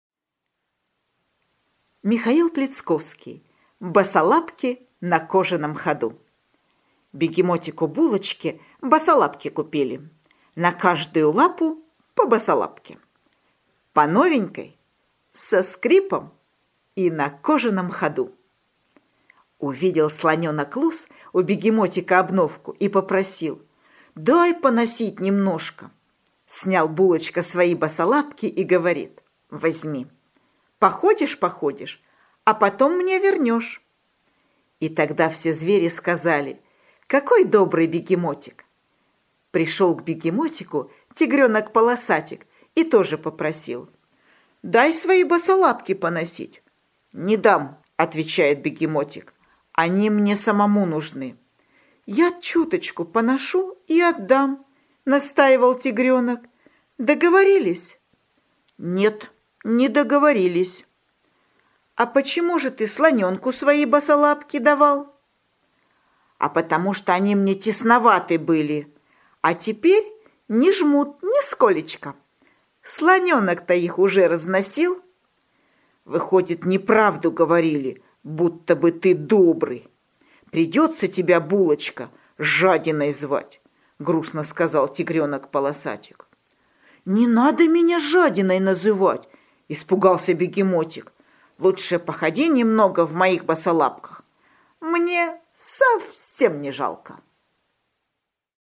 Аудиосказка «Босолапки на кожаном ходу»